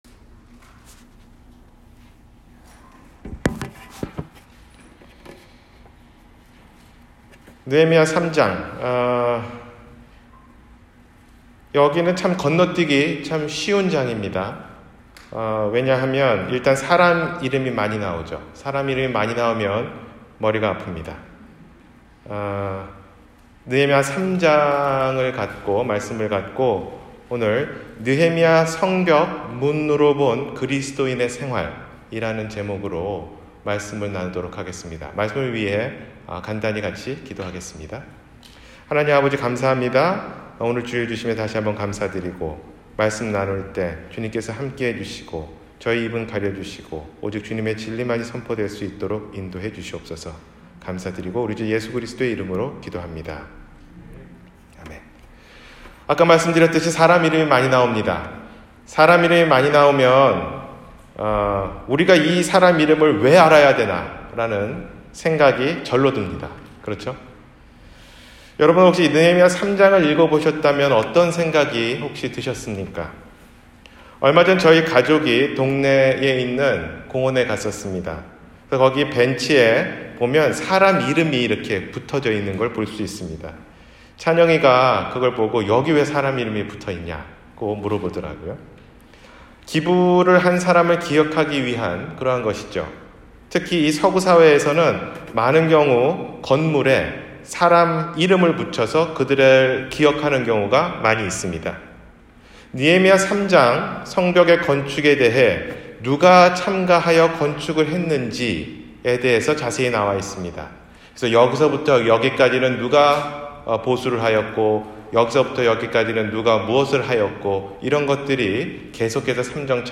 성벽 문을 통해 본 그리스도인의 생활 (느헤미야 3장)- 주일설교 – 갈보리사랑침례교회